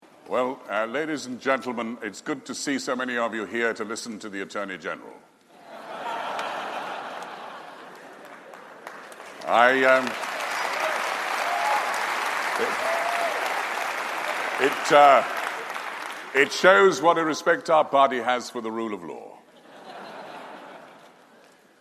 3/10/18 - Conservative Party Conference. AGO Rt Hon Geoffrey Cox was delighted at the attendance for his speech, note his observation about the rule of law.